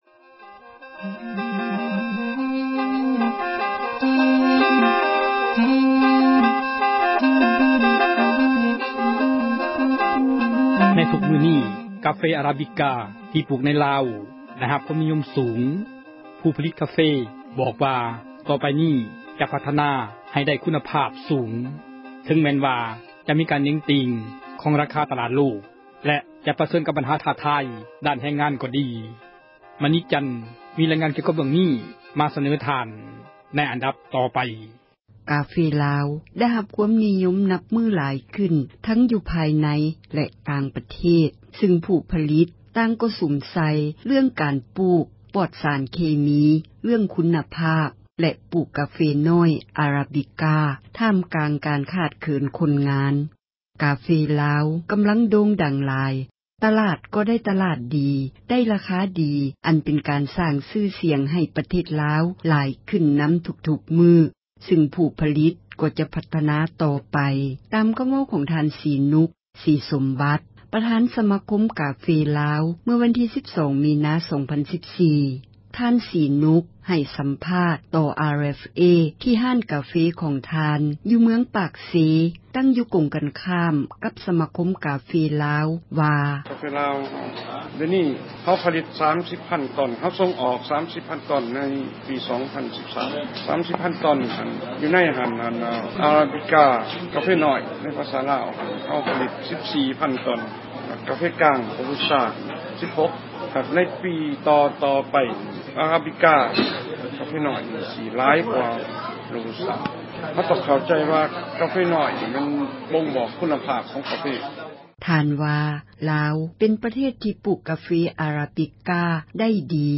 ໃຫ້ ສັມພາດ ຕໍ່ RFA